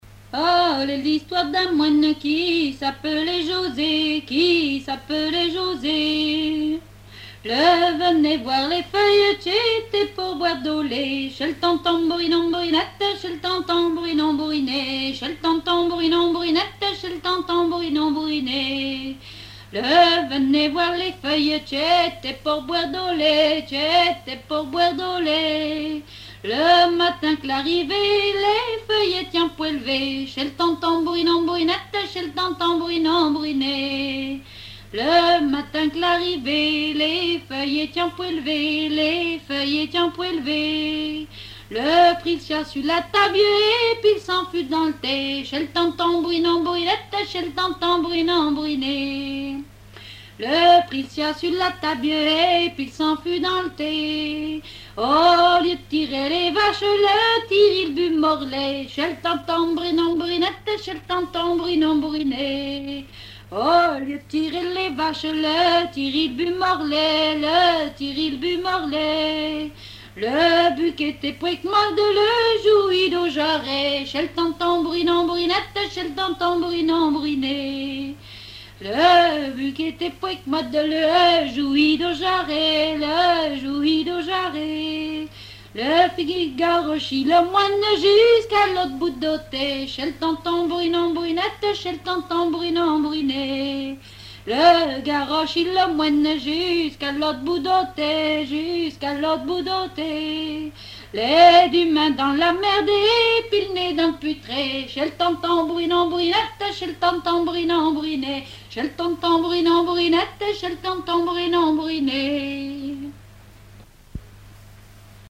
Genre laisse
Enquête Compagnons d'EthnoDoc - Arexcpo en Vendée
répertoire de chansons traditionnelles
Pièce musicale inédite